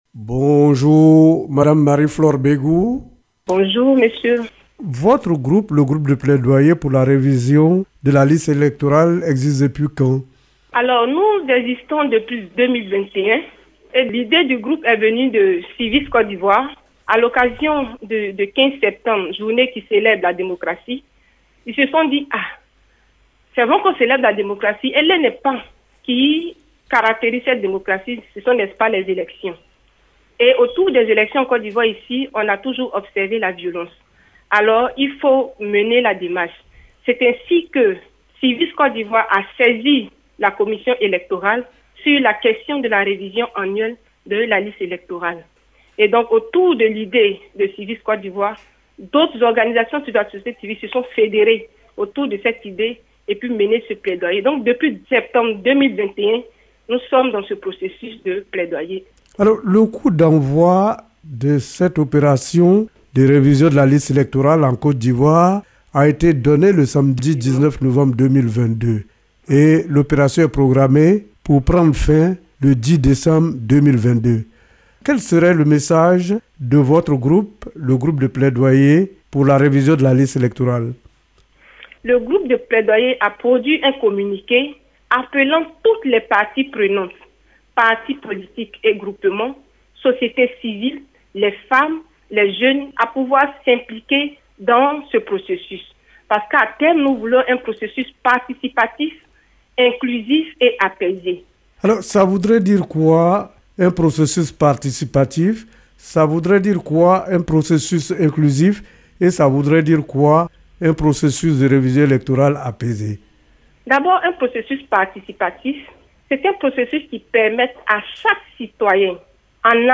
C’est un entretien